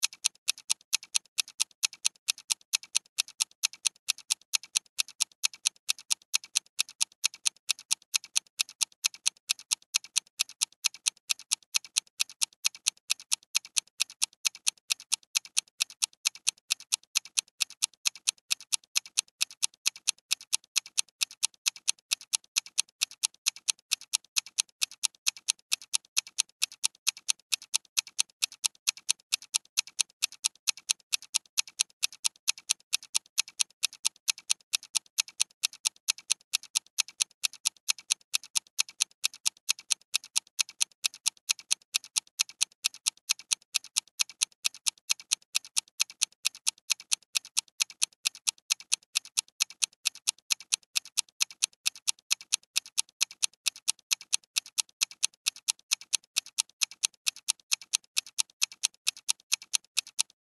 На этой странице собраны звуки викторин — от классических сигналов правильного ответа до зажигательных фанфар.
Звук обратного отсчета, бой часов (1 минута)